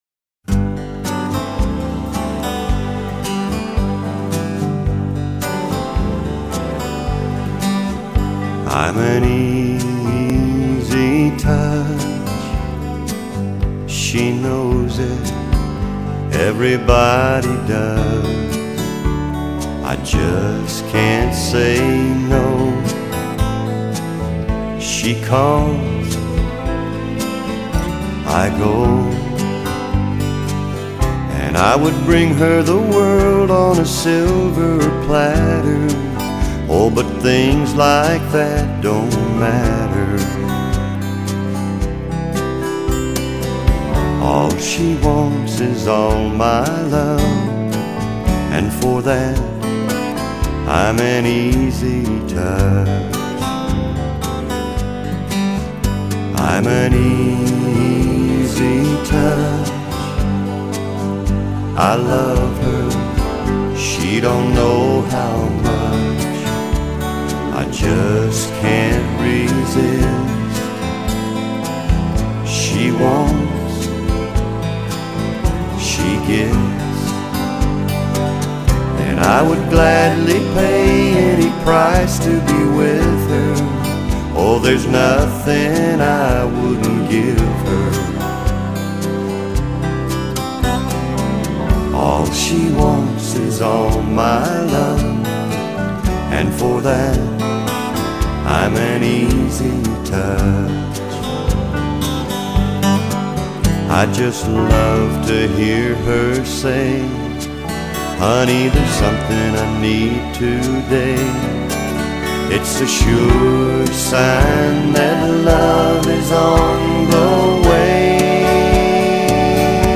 试听曲为第8.10首，这张专辑是录音室录音，不是现场演唱~中间的掌声是我送给他的~`